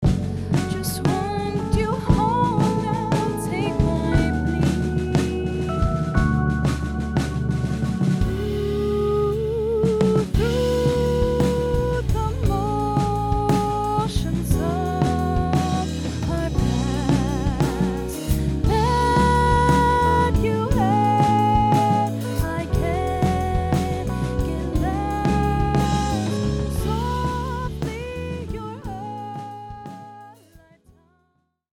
Rehearsal Mix
Rehearsal-Mix.mp3